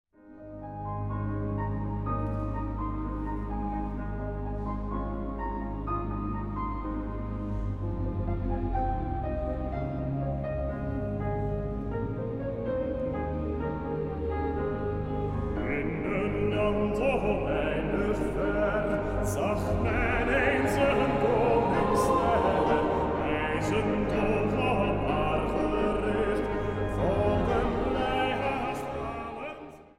Zang | Jongerenkoor